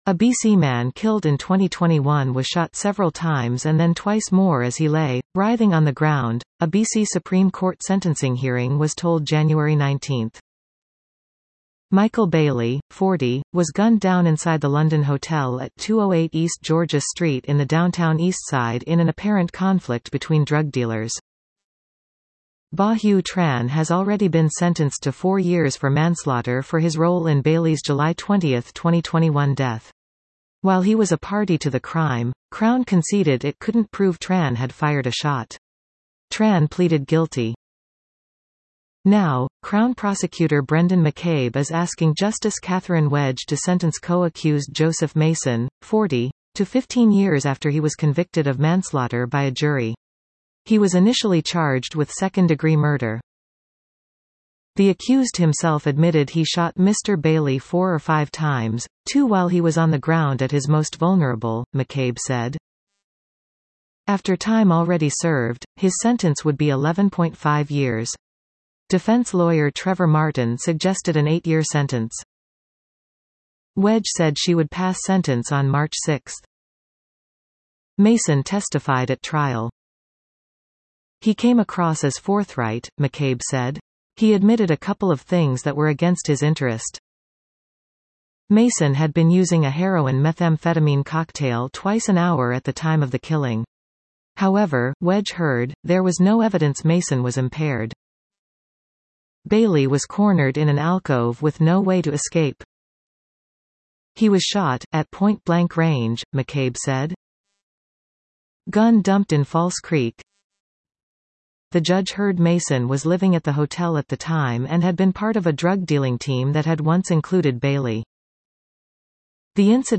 Jeremy Hainsworth Listen to this article 00:04:32 A B.C. man killed in 2021 was shot several times and then twice more as he lay “writhing on the ground,” a B.C. Supreme Court sentencing hearing was told Jan. 19.